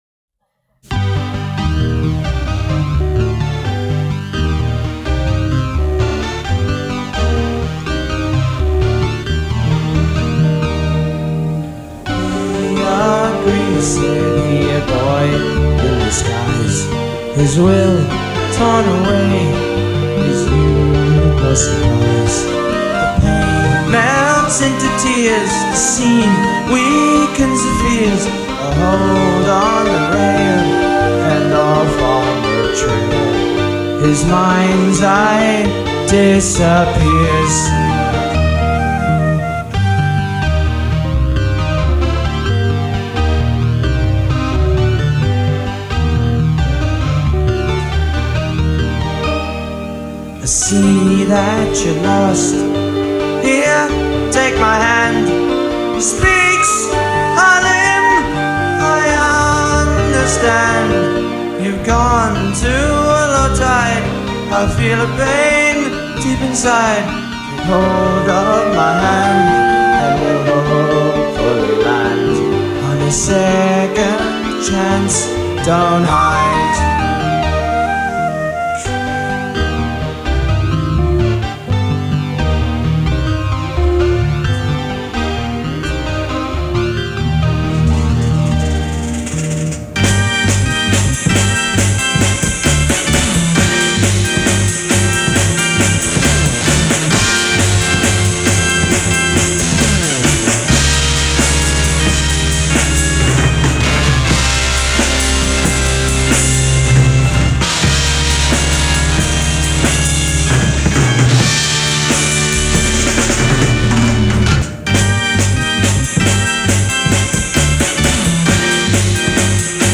The recording quality of some of these leaves a lot to be desired.